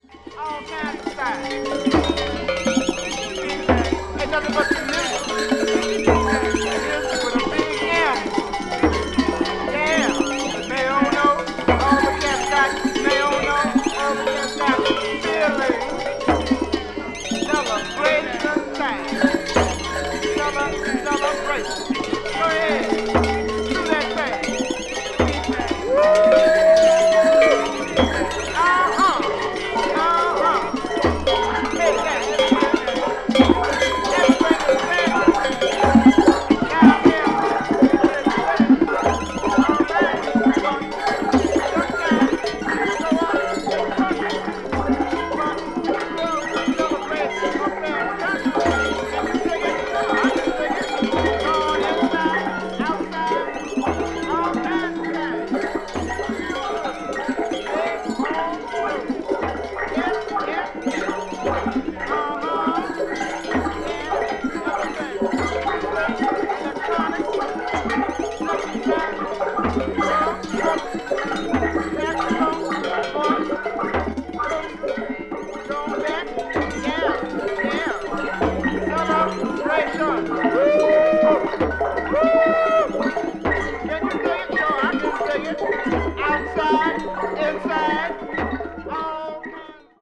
and the chaotic Third World-esque ethnic tune B3